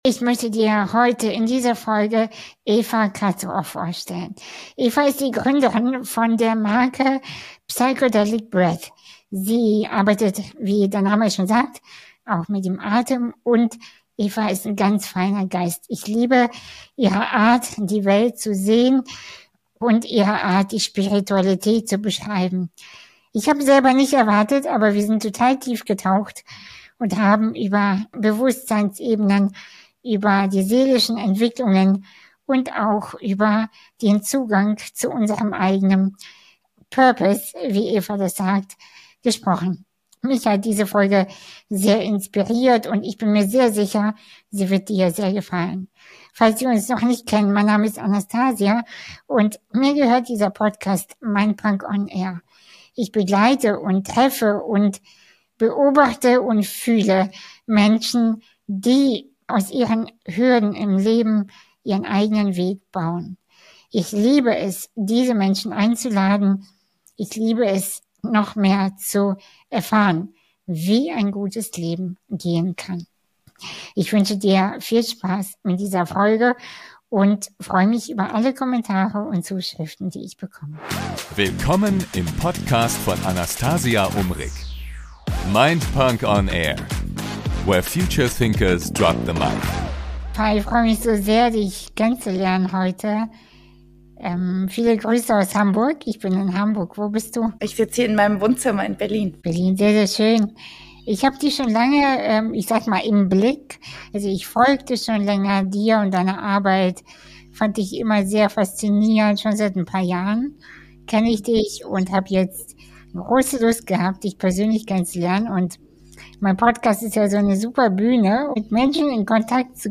Ein Gespräch voller Tiefe, Mut und spiritueller Klarheit.